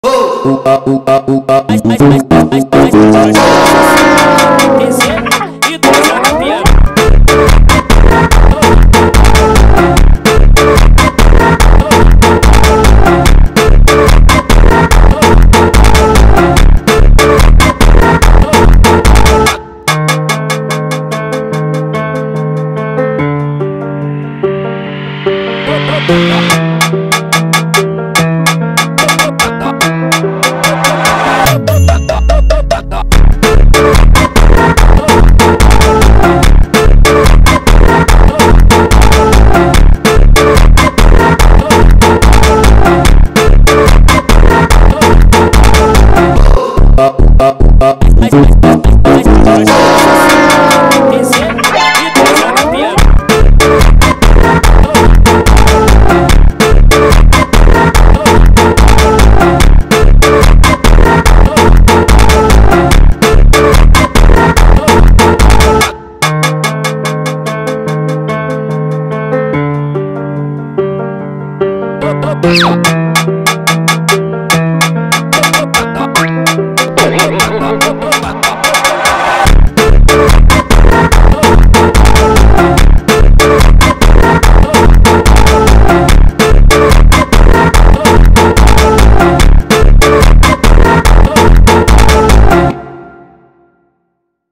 فانک اینستاگرام در ورژن Sped Up
فانک